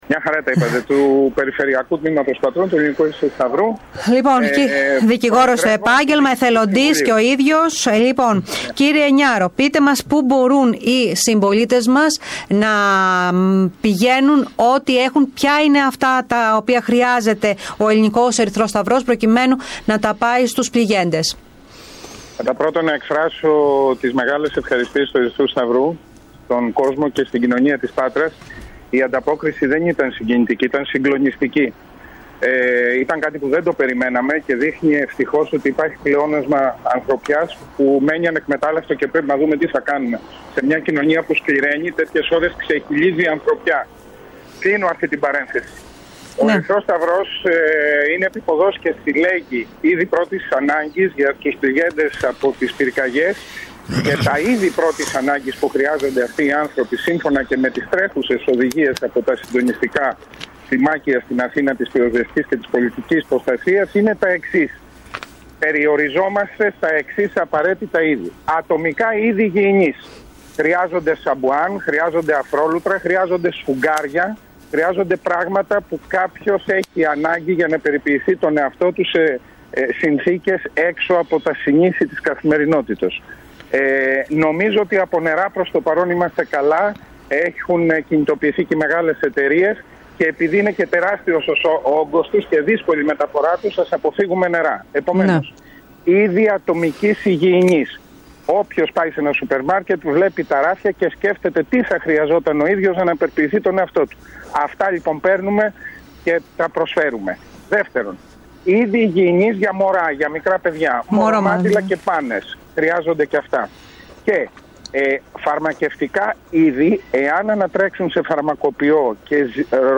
στην ΕΡΤ Πάτρας και στην εκπομπή «Στον αέρα ..μέρα παρά μέρα»